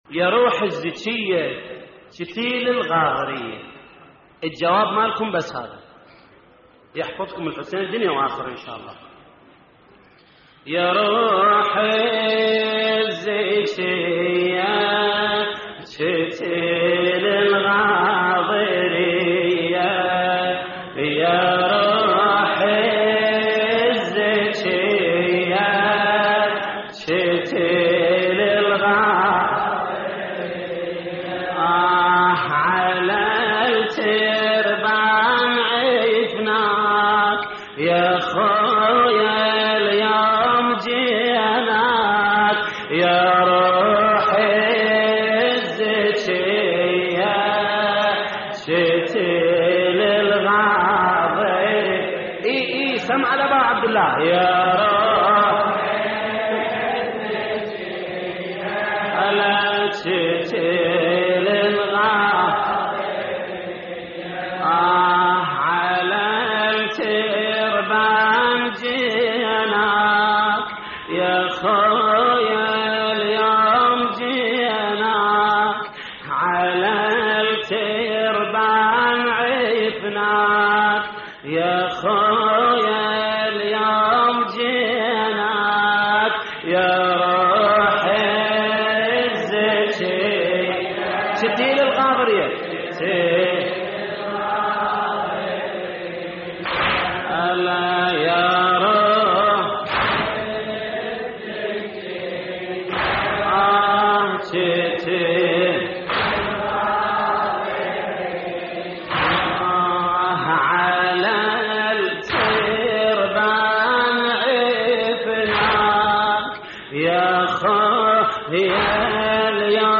تحميل : يا روح الزكية قتيل الغاضرية على التربان عفناك / الرادود جليل الكربلائي / اللطميات الحسينية / موقع يا حسين